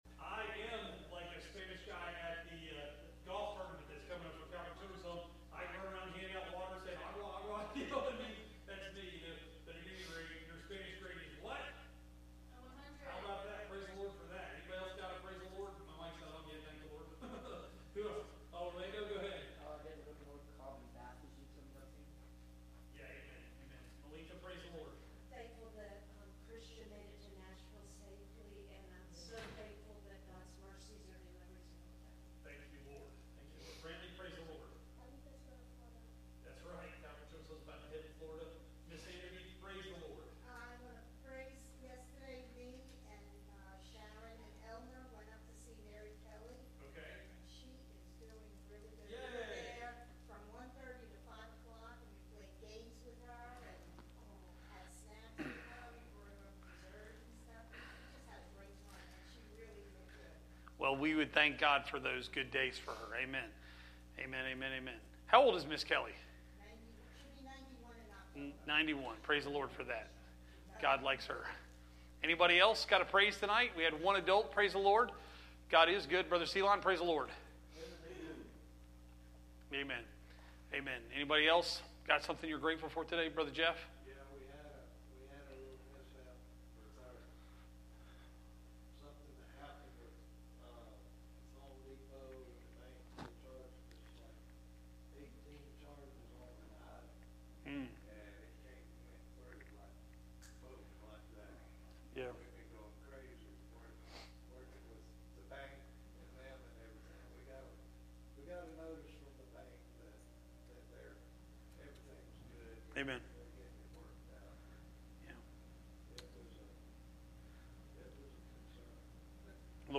Genesis 39:1-6 Service Type: Midweek Meeting « When The Heat Is On